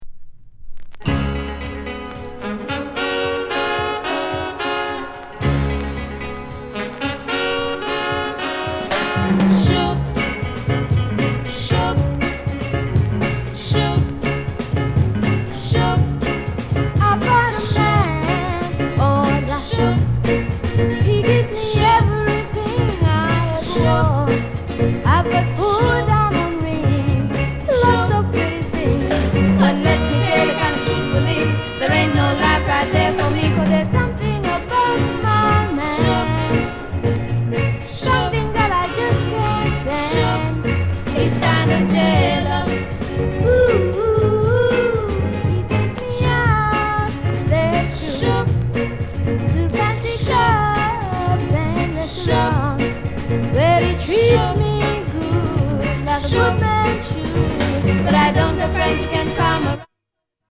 RARE ROCKSTEADY